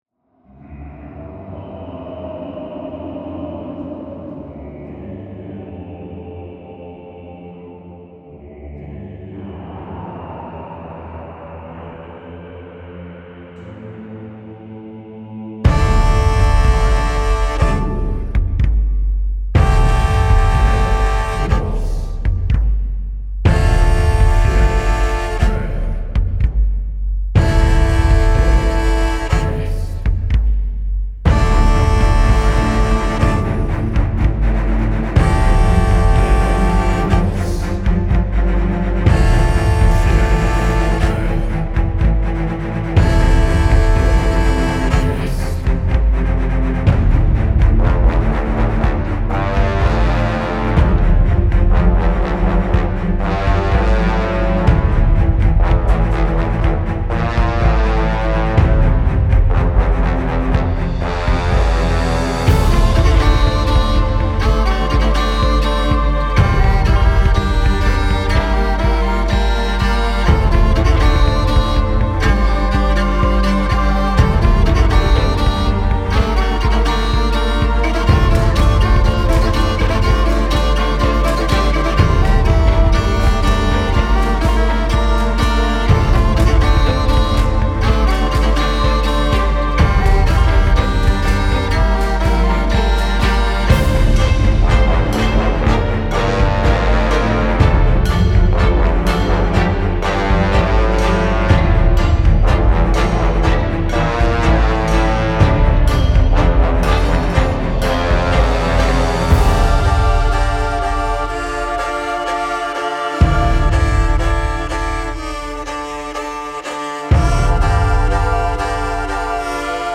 Style Style OrchestralSoundtrackWorld
Mood Mood DarkEpicIntenseMysteriousUplifting
Featured Featured BrassChoirDrumsPercussionStringsViolin
BPM BPM 123